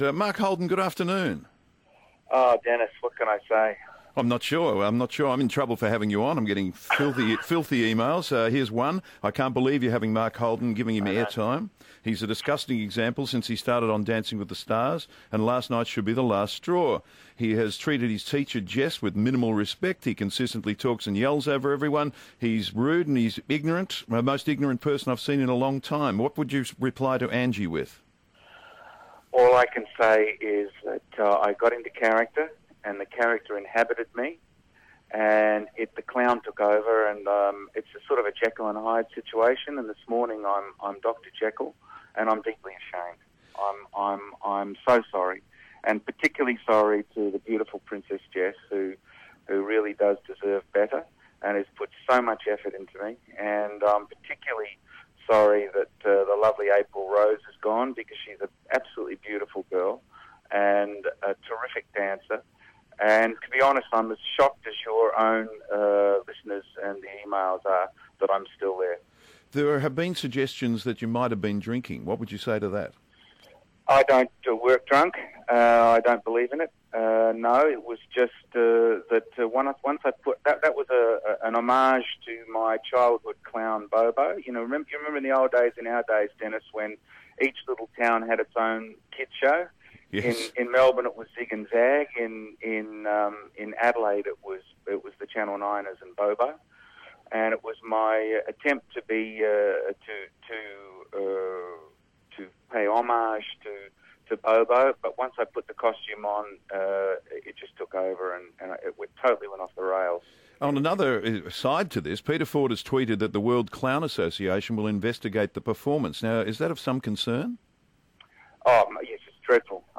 Denis Walter speaks to Mark Holden after his controversial performance.